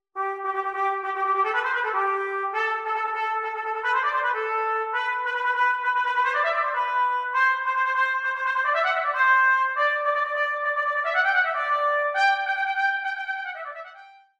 Trumpet Solo
A short extract: Tonguing